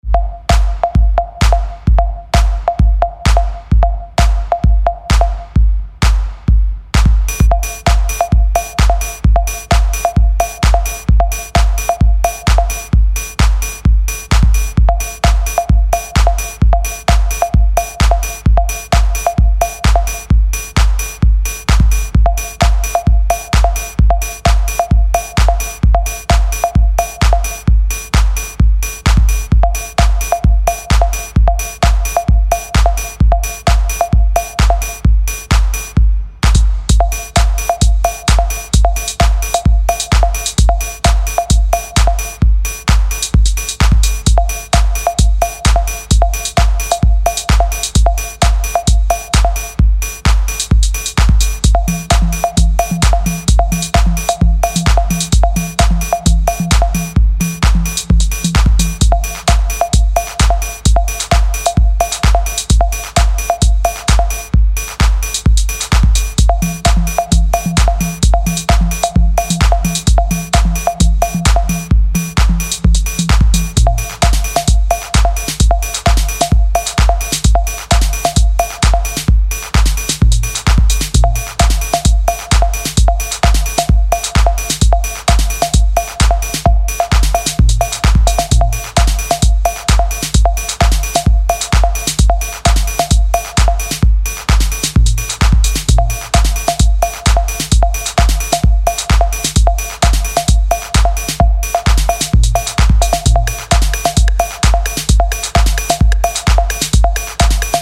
Remastered reissue
Techno